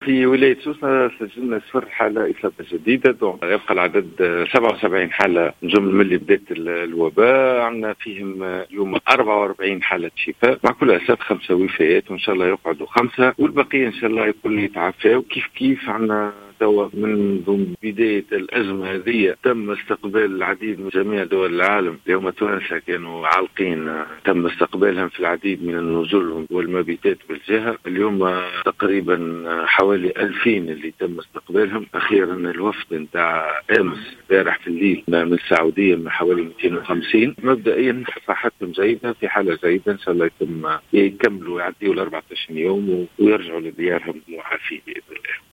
أكد المدير الجهوي للصحة بسوسة، سامي الرقيق في تصريح اليوم لـ"الجوهرة أف أم" عدم تسجيل إصابات جديدة بفيروس "كورونا" في الجهة.